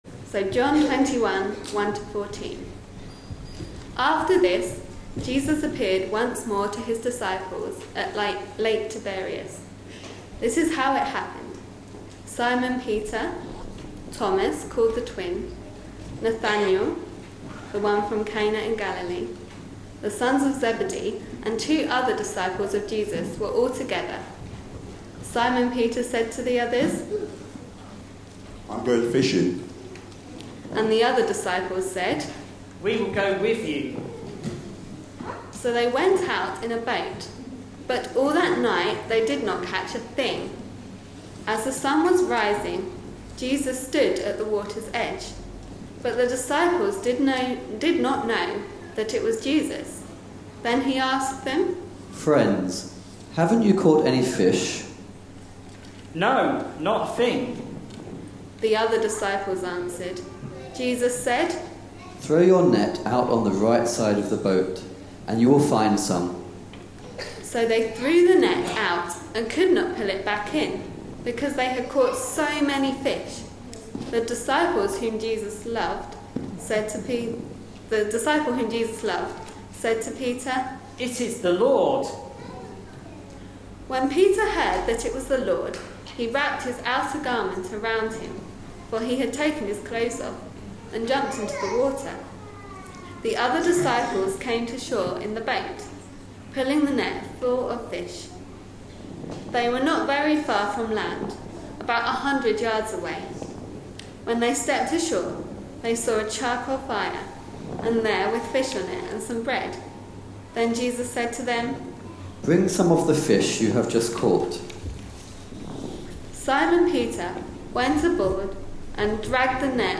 A sermon preached on 31st August, 2014, as part of our On The Beach. series.
John 21:1-14 Listen online Details Reading is John 21:1-14, with multiple voices.